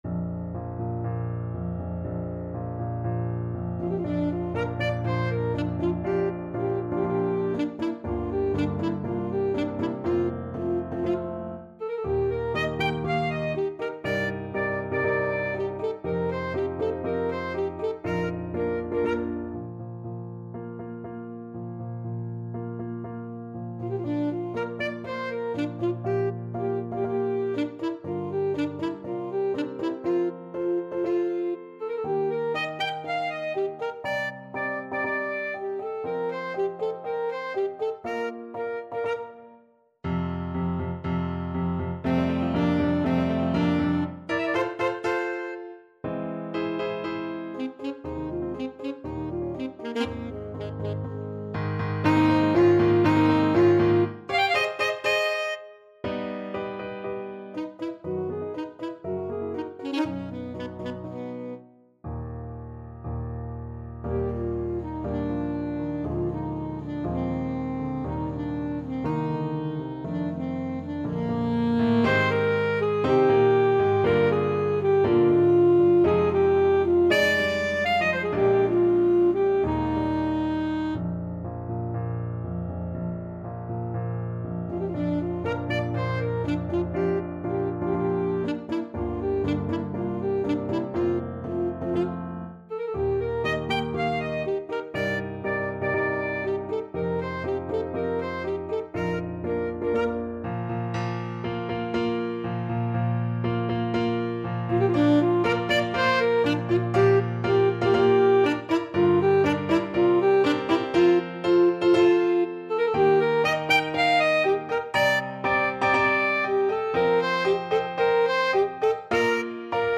Classical Grieg, Edvard Wedding Day at Troldhaugen from Lyric Pieces Op.65 Alto Saxophone version
~ = 120 Tempo di Marcia un poco vivace
Bb major (Sounding Pitch) G major (Alto Saxophone in Eb) (View more Bb major Music for Saxophone )
Classical (View more Classical Saxophone Music)
grieg_op-65_troldhaug_ASAX.mp3